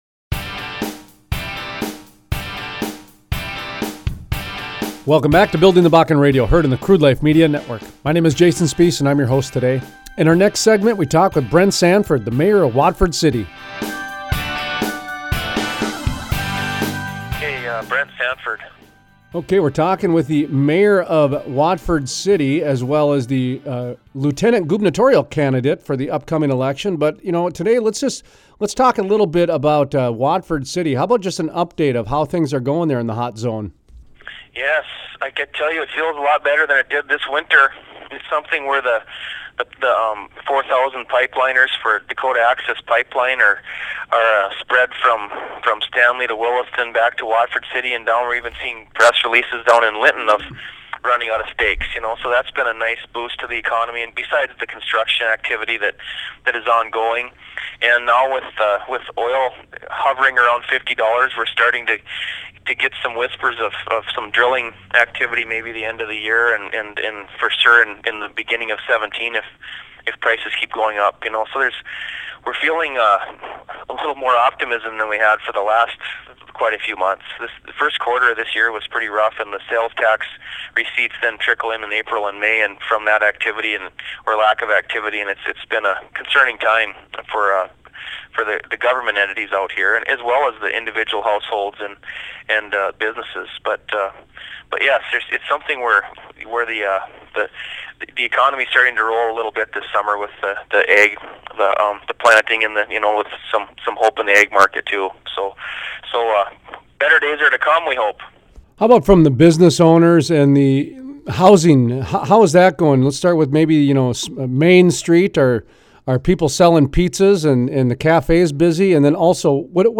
Interviews: Brent Sanford, Mayor, Watford City Gives an update on the Hot Zone in the Bakken from local businesses to housing to hotels.